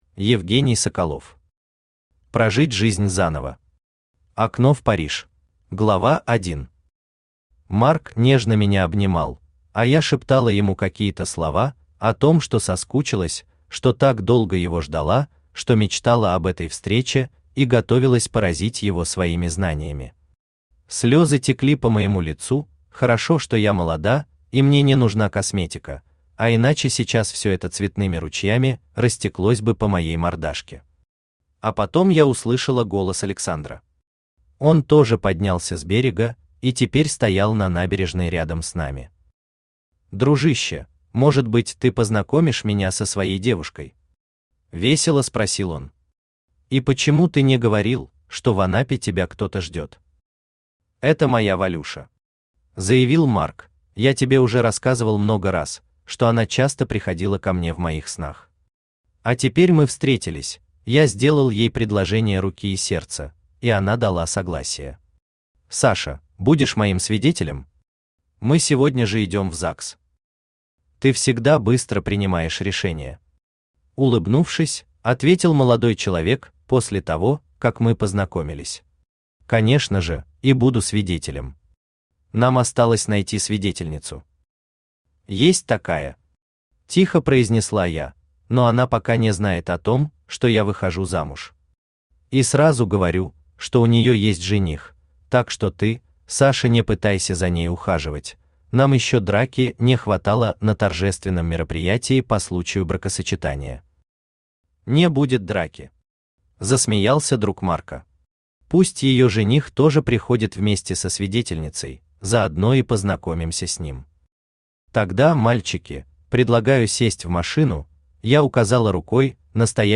Аудиокнига Прожить жизнь заново. Окно в Париж | Библиотека аудиокниг
Окно в Париж Автор Евгений Владимирович Соколов Читает аудиокнигу Авточтец ЛитРес.